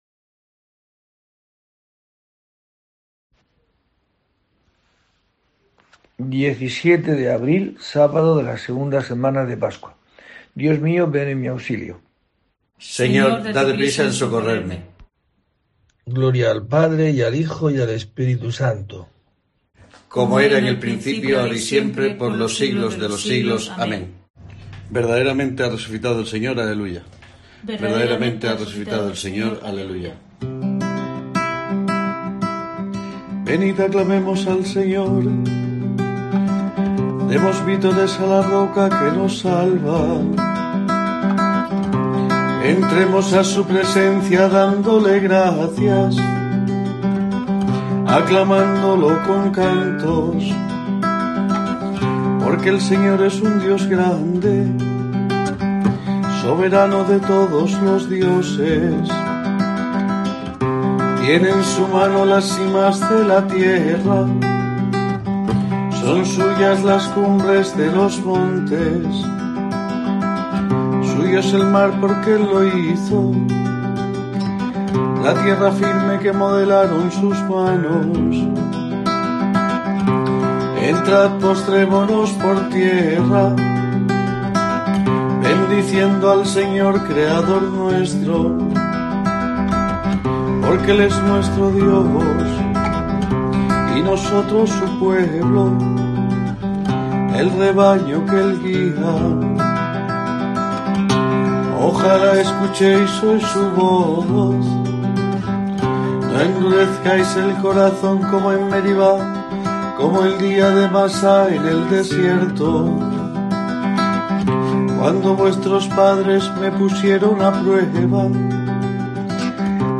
17 de abril: COPE te trae el rezo diario de los Laudes para acompañarte